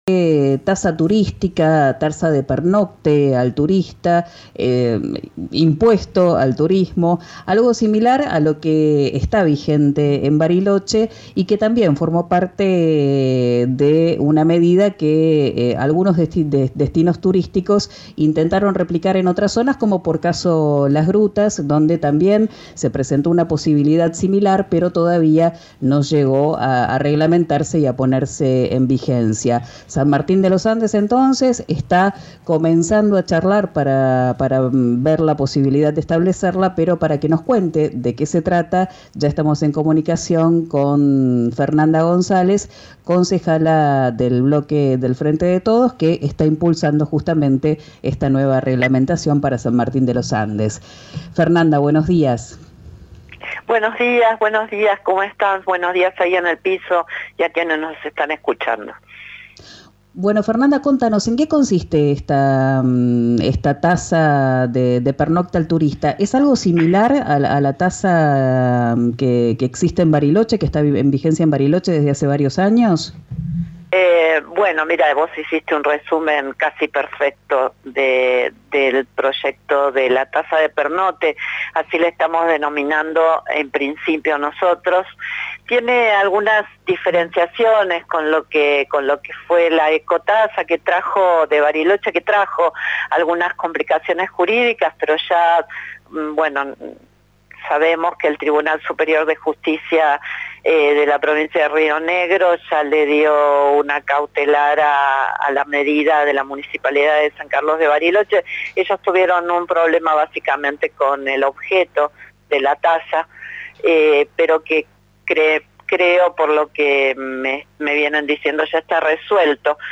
Escuchá a la concejala Fernández González en RÍO NEGRO RADIO.